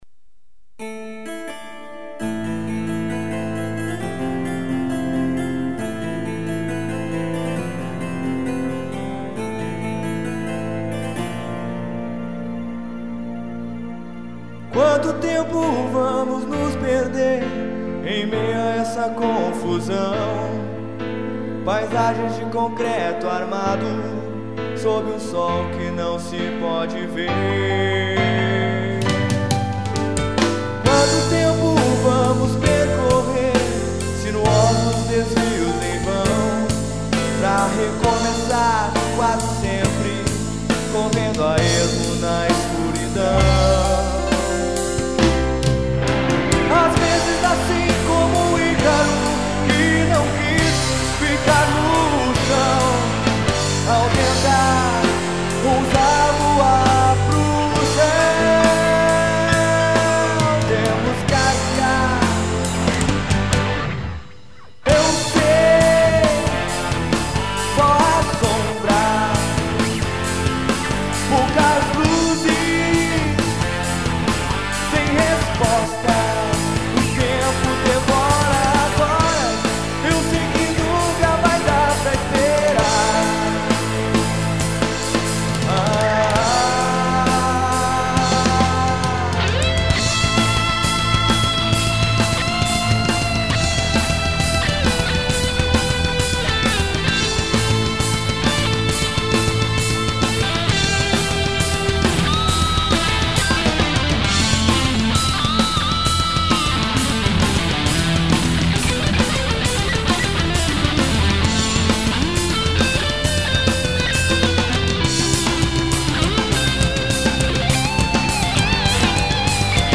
EstiloHard Rock